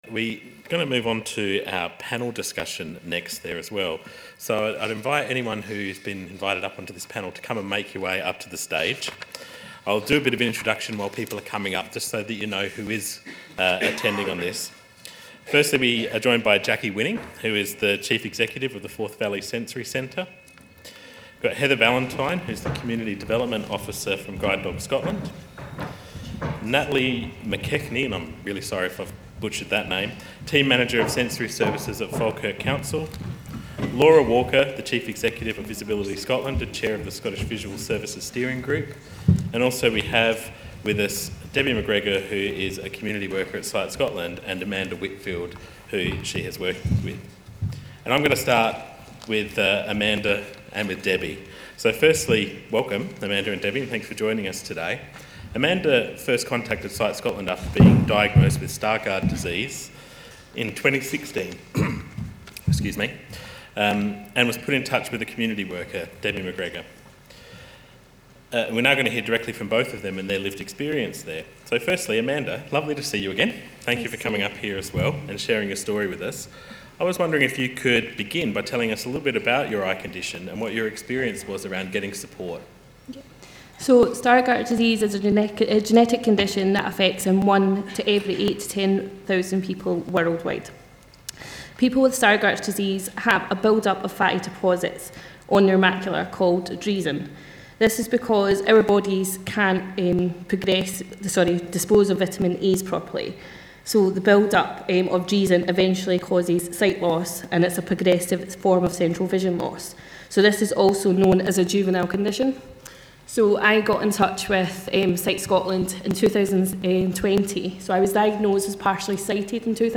Scottish Vision Strategy Conference 2023 - Panel Discussion